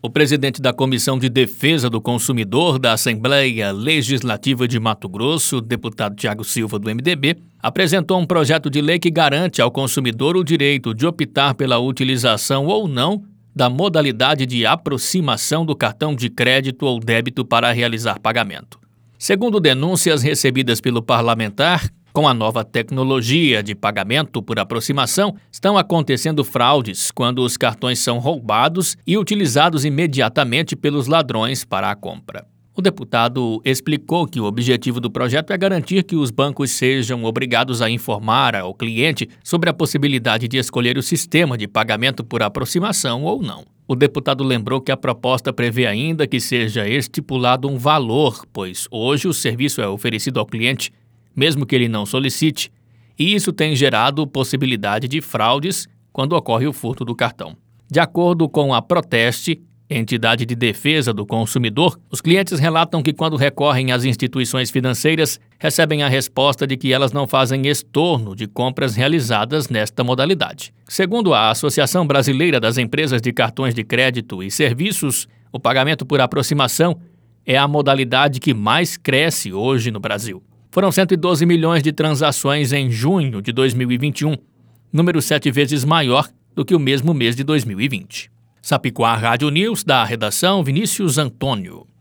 Boletins de MT 18 fev, 2022